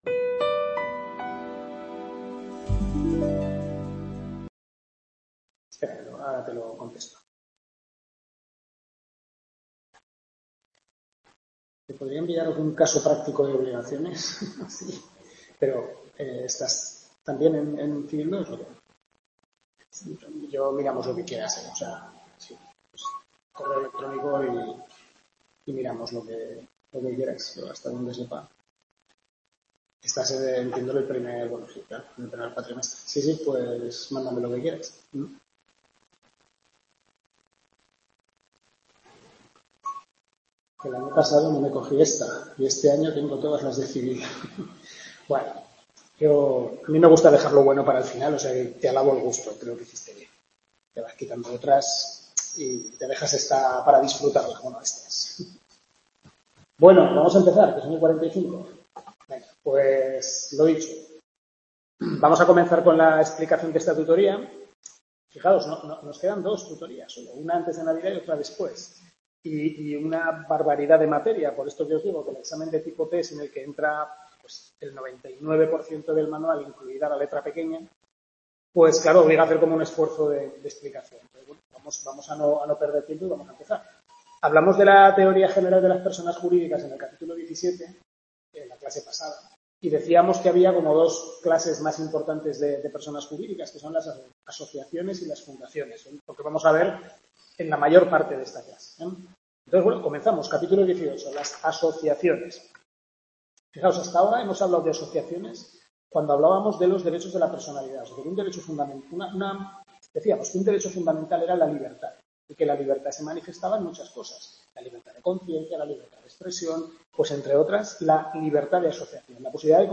Tutoría 5/6 Civil I, primer cuatrimestre (Parte General y Persona), centro UNED de Calatayud, capítulos 18-21 del Manual del Profesor Lasarte